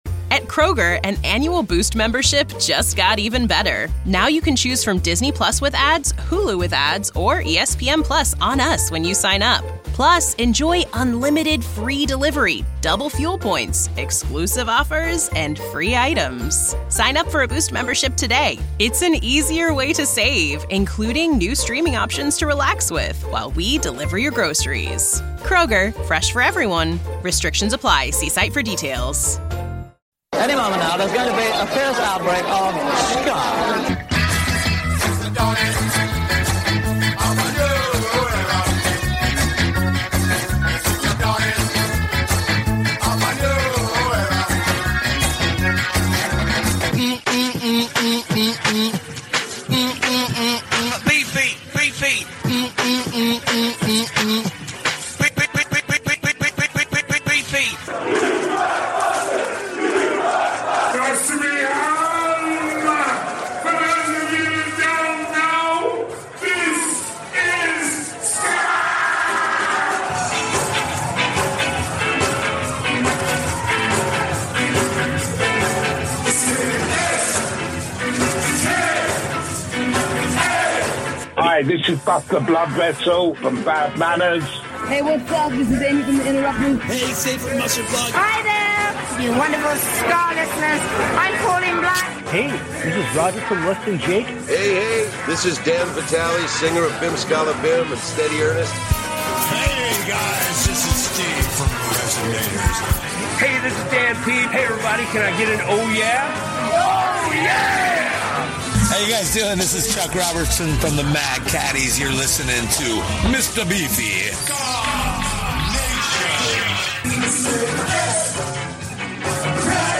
TELL A FRIEND** **THE WORLD'S #1 SKA SHOW - SKA NATION RADIO - FOR YOUR LISTENING PLEASURE ** KEEP SPREADING THE GOSPEL OF SKA!